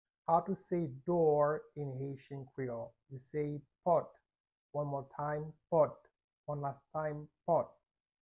How to say "Door" in Haitian Creole - "Pòt" pronunciation by a native Haitian teacher
“Pòt” Pronunciation in Haitian Creole by a native Haitian can be heard in the audio here or in the video below:
How-to-say-Door-in-Haitian-Creole-Pot-pronunciation-by-a-native-Haitian-teacher.mp3